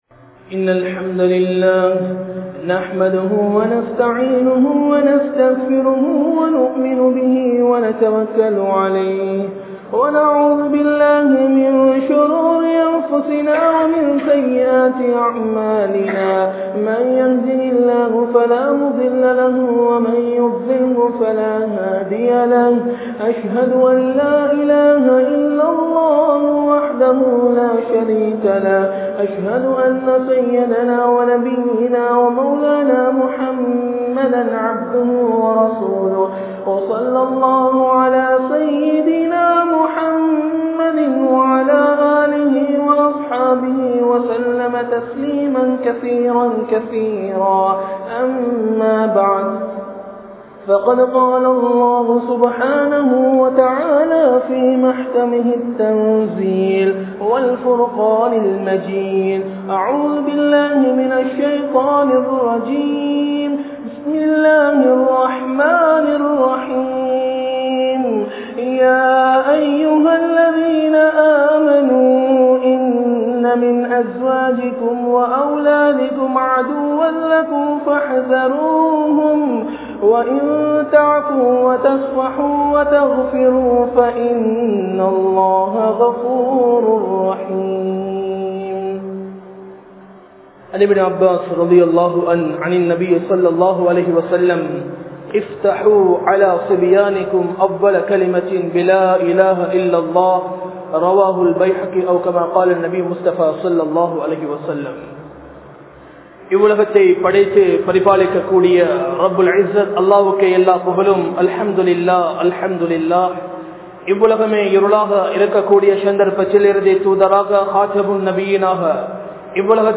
Kulanthaihalai Seeralikkum Cartoon (குழந்தைகளை சீரழிக்கும் கார்டூன்) | Audio Bayans | All Ceylon Muslim Youth Community | Addalaichenai